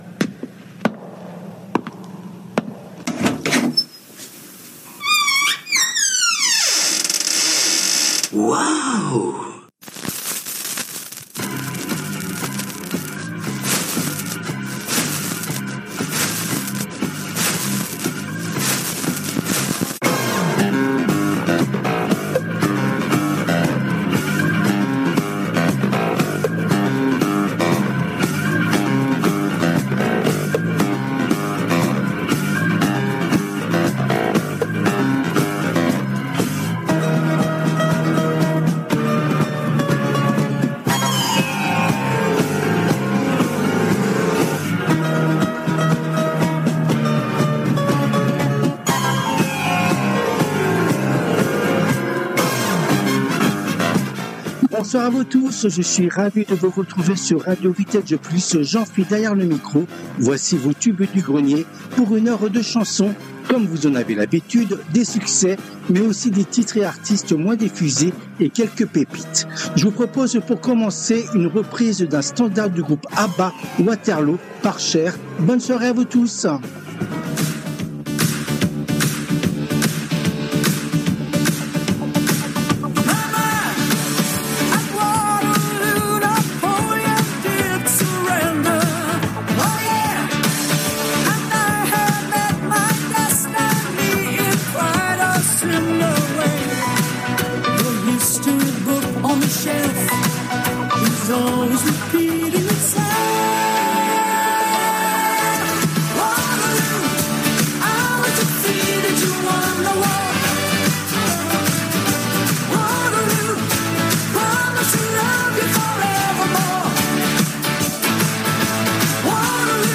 Les TUBES DU GRENIER constituent la première émission hebdomadaire phare de la semaine sur RADIO VINTAGE PLUS et cette édition a été diffusée en direct le mardi 18 mars 2025 à 19h depuis les studios de RADIO RV+ à PARIS .